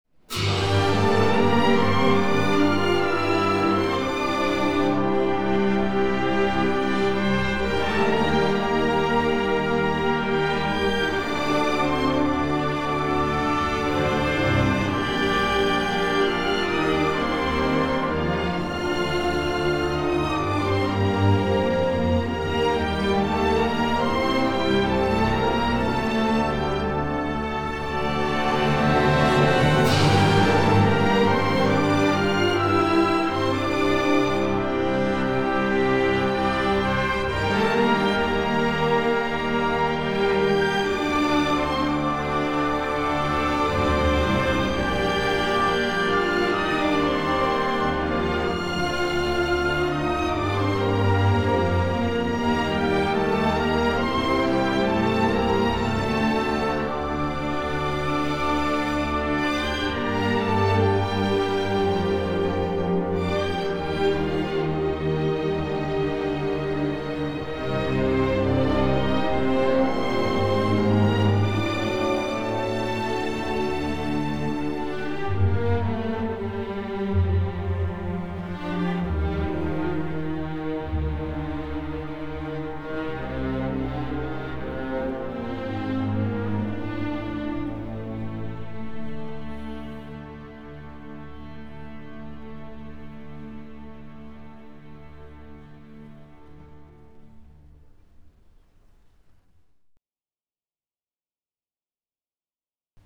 【交响乐与史诗的珠联璧合】
民乐与西乐水融，相得益彰，诠释泱泱大秦文化神髓，将深遂曲意淋漓呈现，成全了交响乐与史诗的珠联璧合。